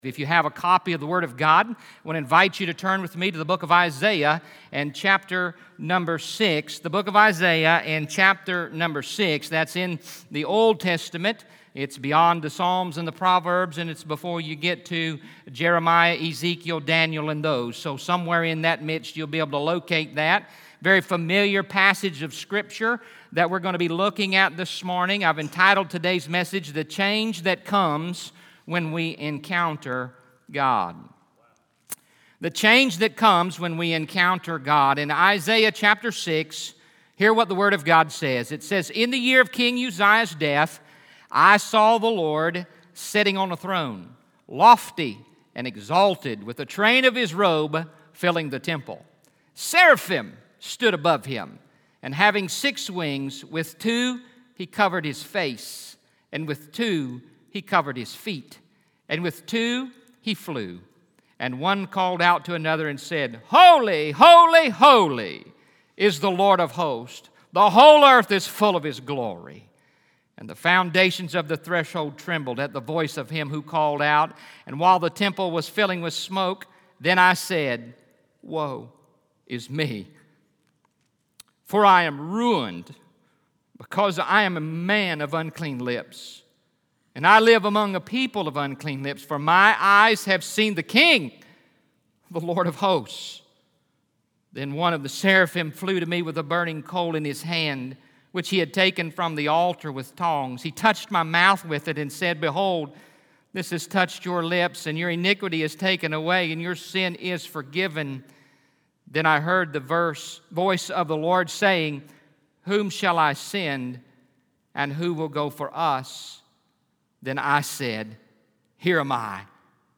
From the morning session of the Real Momentum Conference on Saturday, August 3, 2019